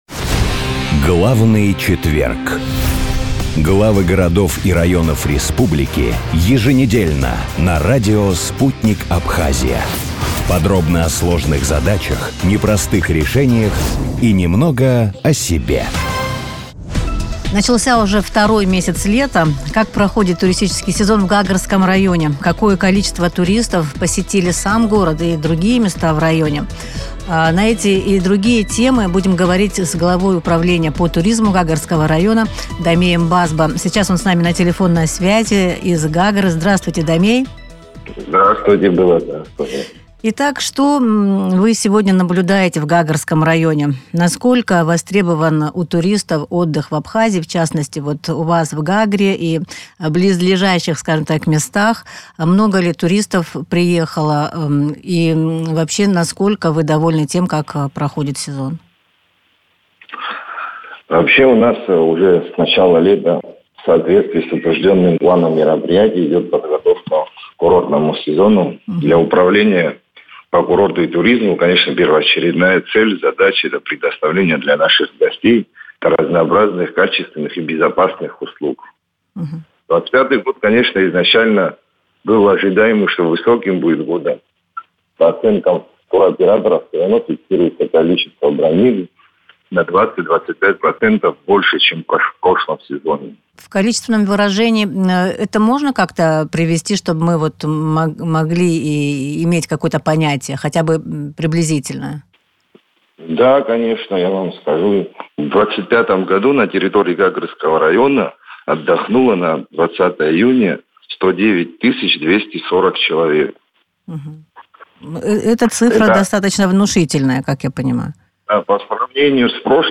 Начальник Управления по туризму администрации Гагрского района Дамей Базба в эфире радио Sputnik рассказал, как проходит турсезон, как много туристов посетило курортный город в первый месяц лета и какой проноз до конца сезона.